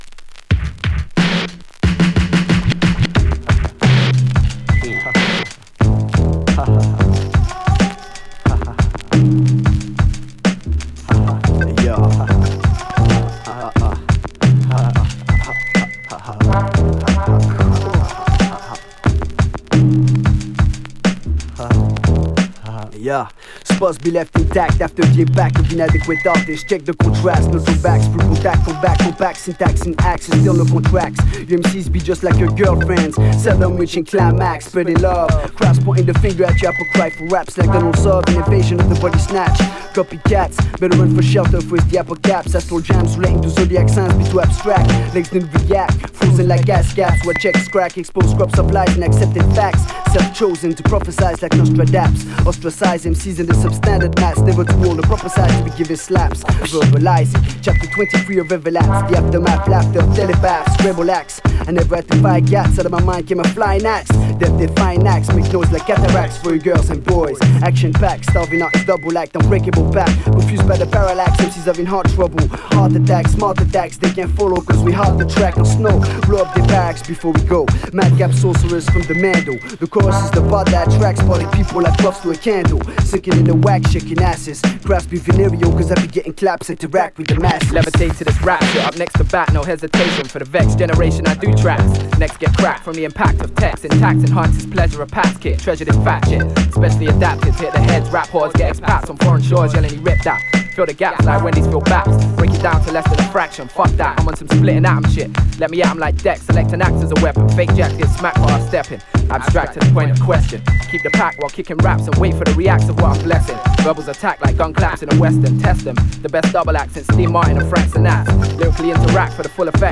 ※チリノイズあり